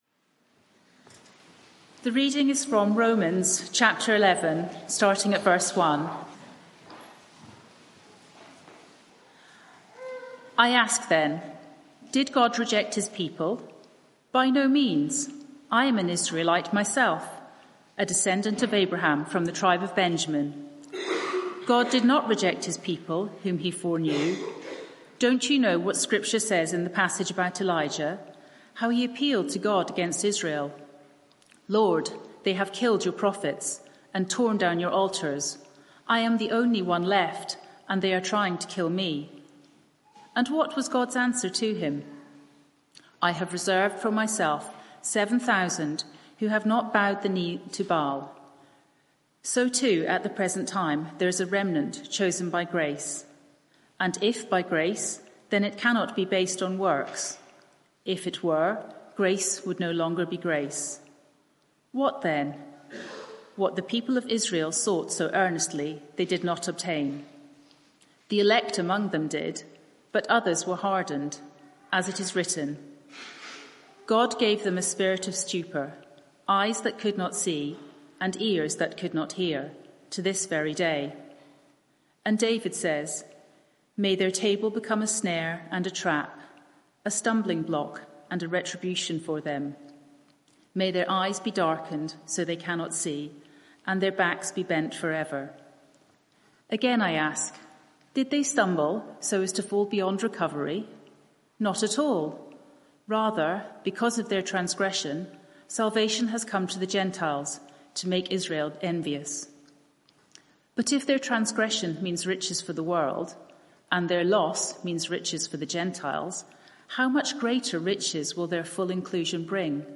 Media for 11am Service on Sun 13th Oct 2024 11:00 Speaker
Sermon